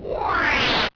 whoosh.wav